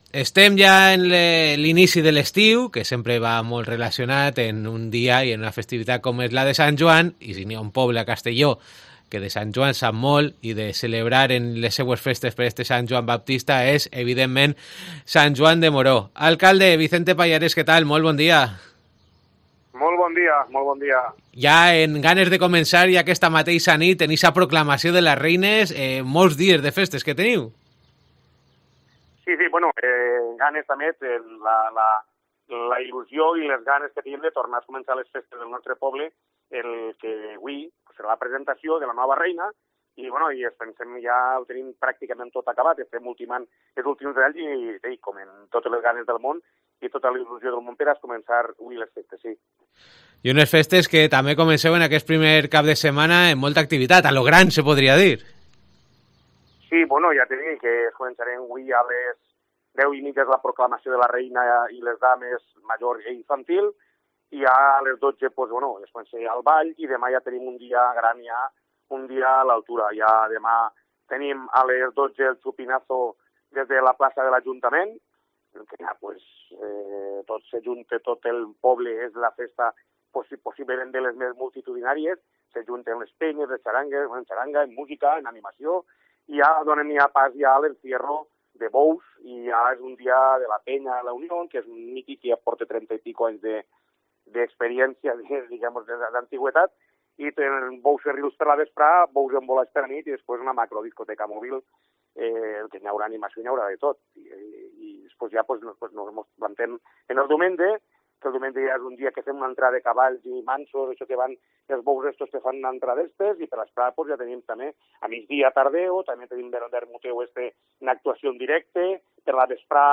Lo hemos analizado en COPE con su alcalde, Vicente Pallarés.